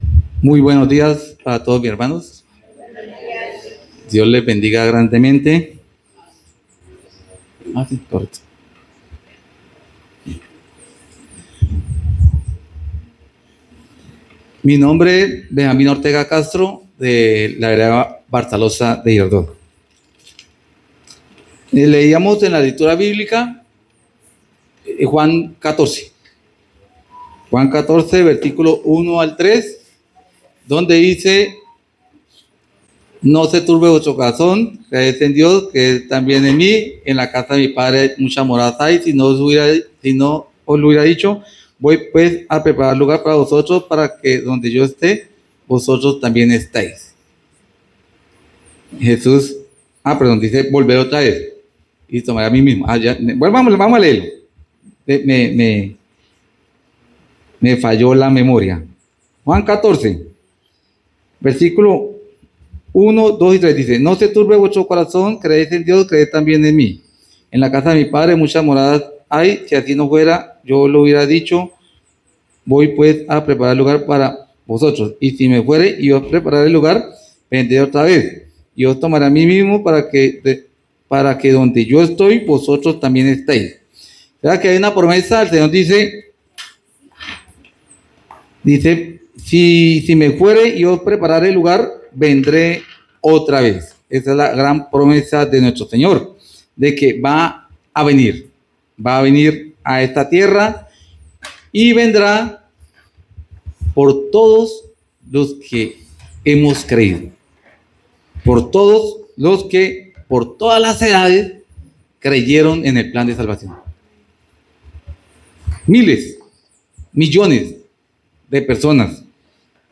Predicaciones Segundo Semestre 2025